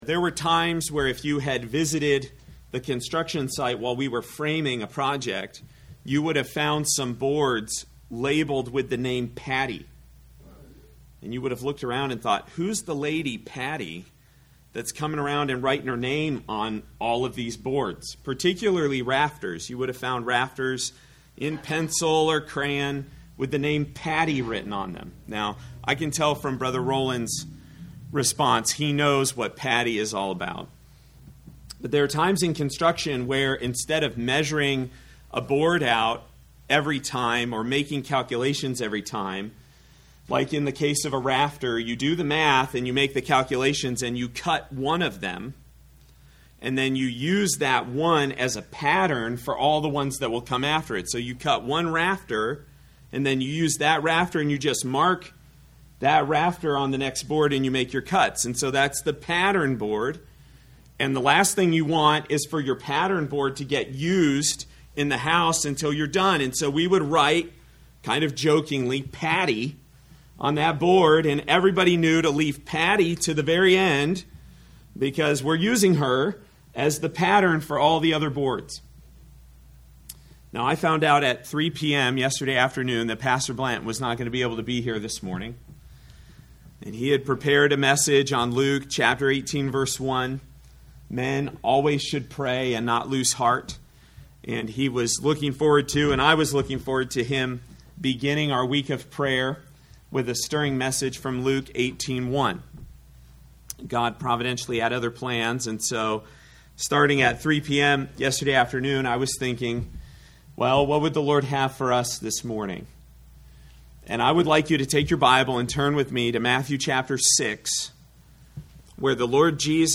A message from the series "Meditations on Prayer."